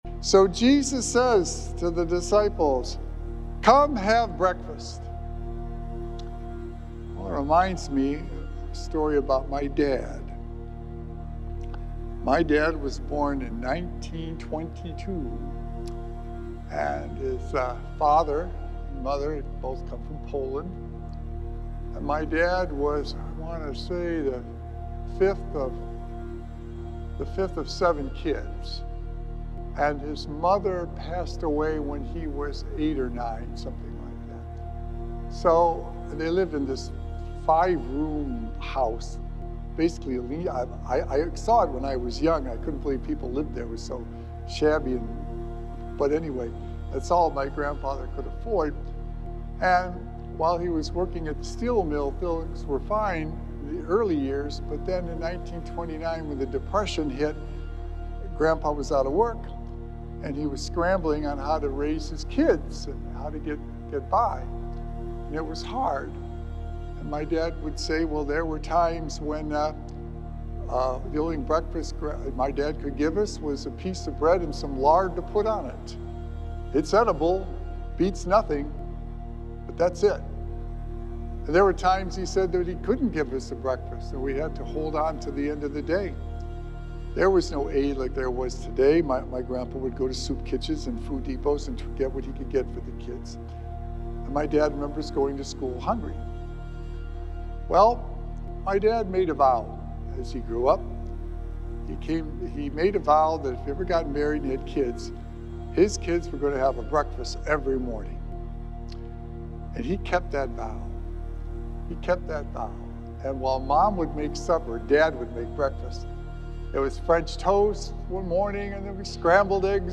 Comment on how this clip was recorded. Recorded Live on Sunday, May 4th, 2025 at St. Malachy Catholic Church.